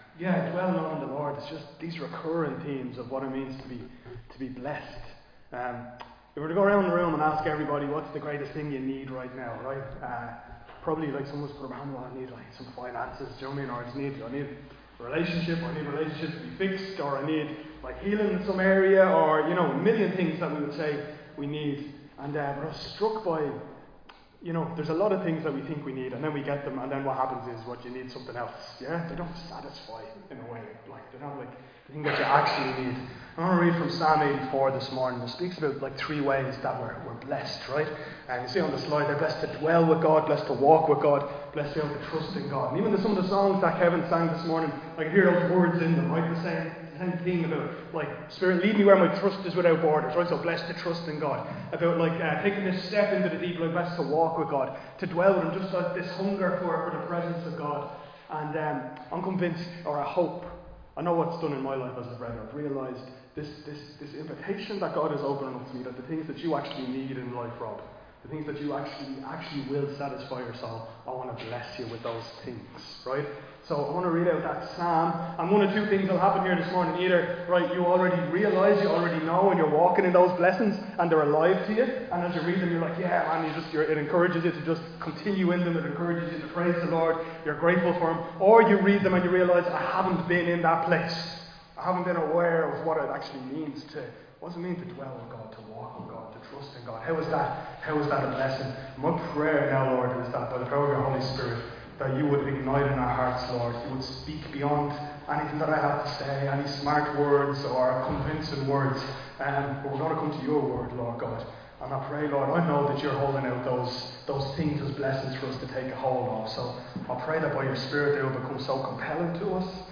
Recorded live in Liberty Church on 21 September 2025